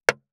460,切る,包丁,厨房,台所,野菜切る,咀嚼音,ナイフ,調理音,まな板の上,料理,
効果音厨房/台所/レストラン/kitchen食材